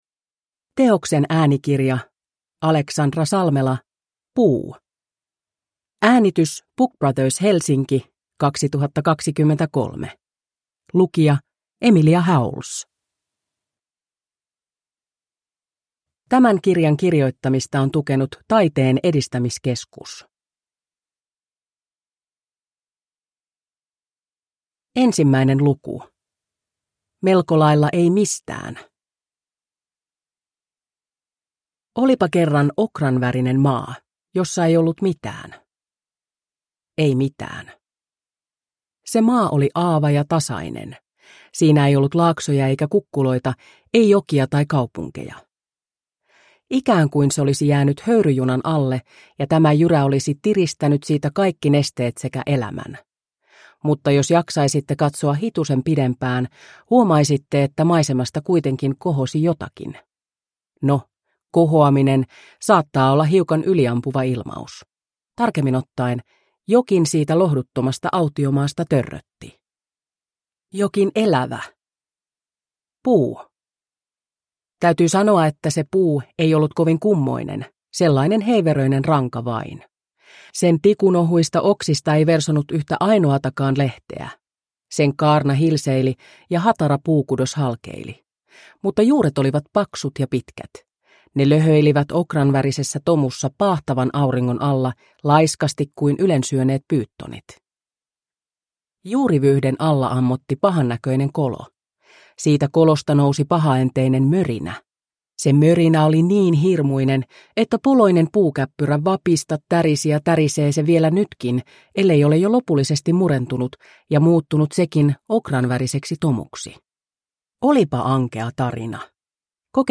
Puu (ljudbok) av Alexandra Salmela